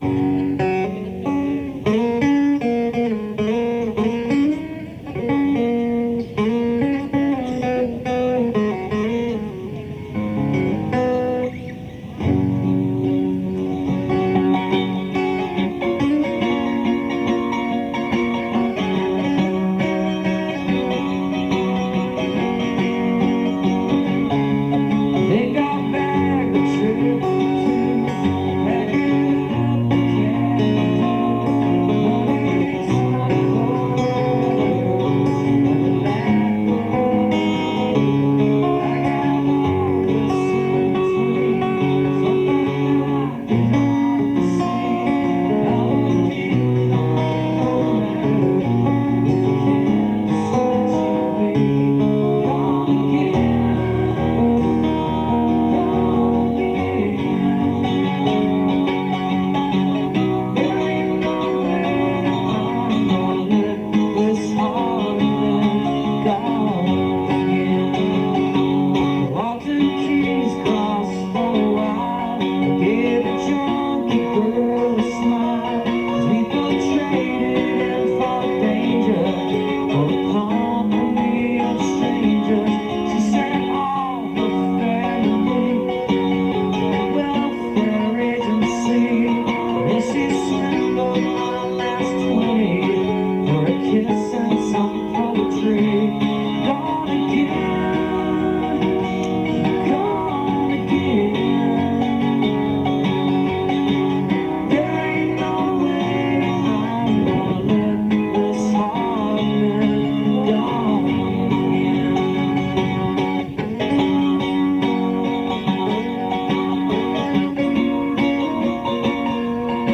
(soundcheck)